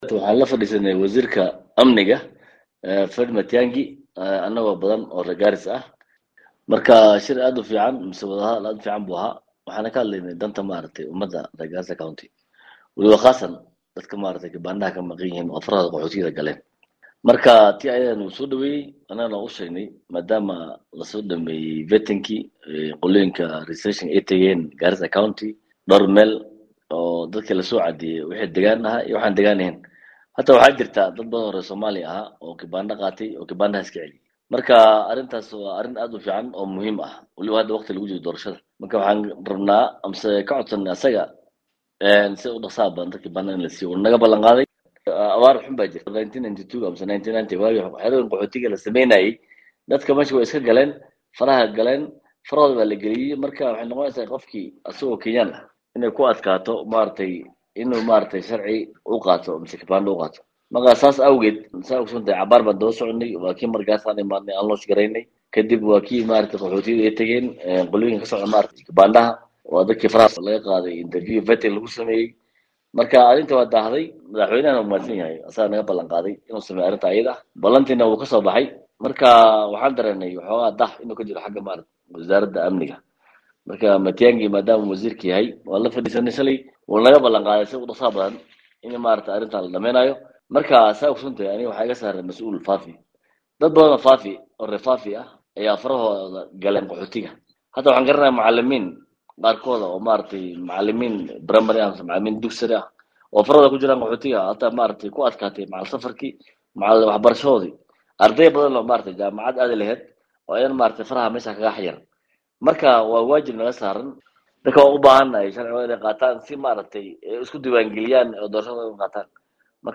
Xildhibaanda la kulmay wasiirka arimaha gudaha ayaa waxa kamid ahaa Xildhibaanka laga soo doortay kursiga Baarlamanka e FAFI Cadbikarim Cismaan o idaacada STAR FM faa faahin ka siiyay shirka ay la qaateen Matiangi.